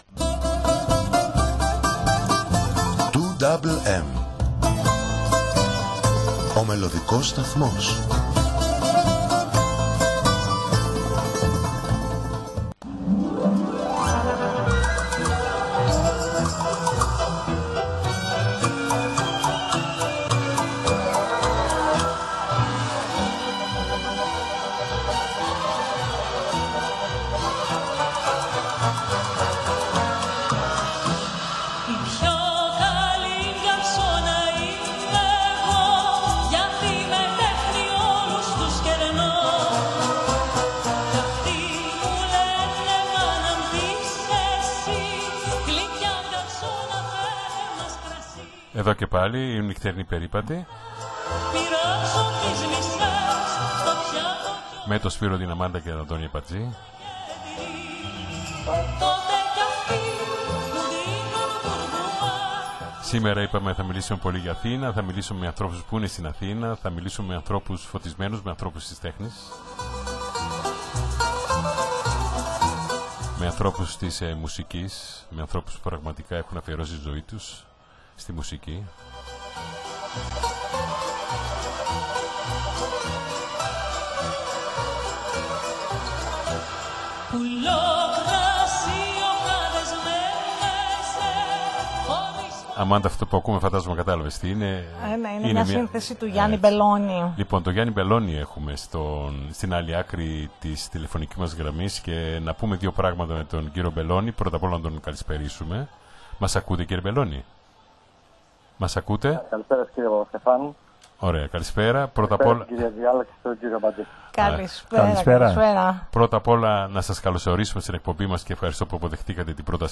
ζωντανή συνέντευξη